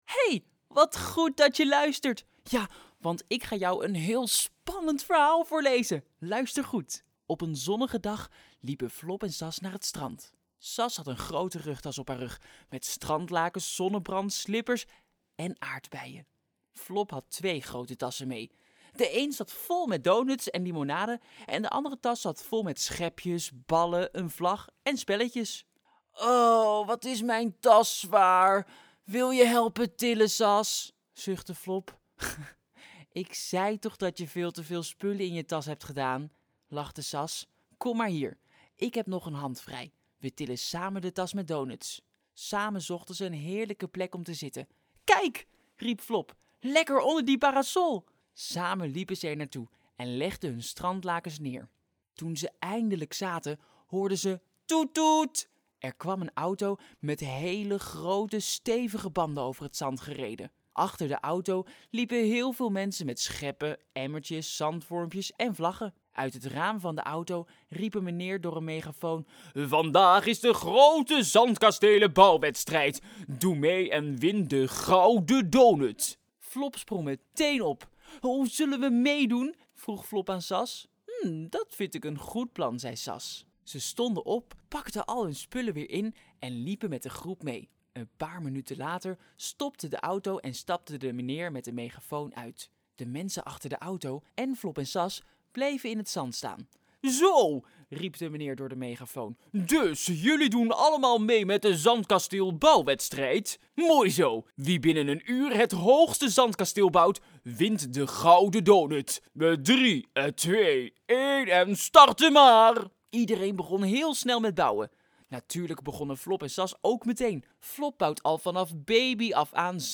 Luisterverhalen